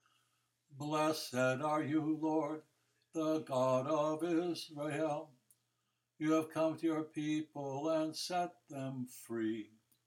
Benedictus – 4.4 – Easy chants and committed language for the Daily Office
Benedictus-G-Tone-4.4.m4a